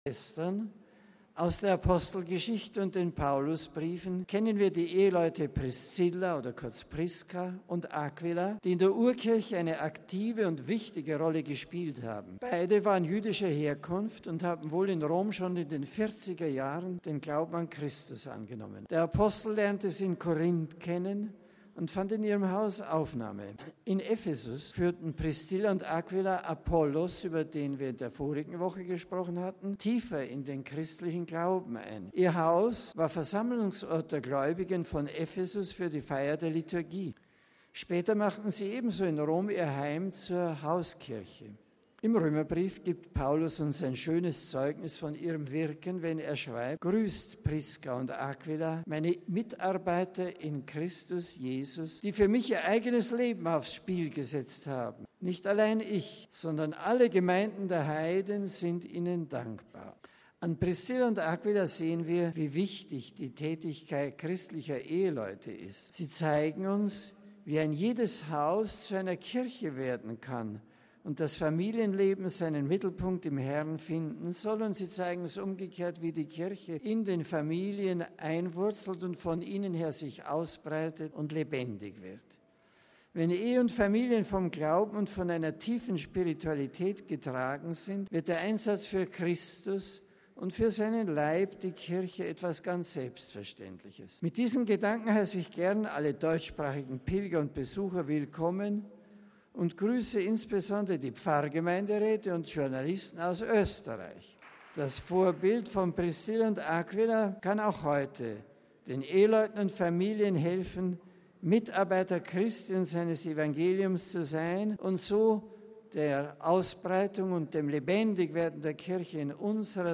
MP3 In der Katechese der Generalaudienz hat Benedikt XVI. die Reihe über große Gestalten der Urkirche fortgesetzt. In der Audienzhalle sprach er heute von den Eheleuten Priszilla und Aquila. Ehe sei Hauskirche, sagte der Papst den deutschsprachigen Pilgern.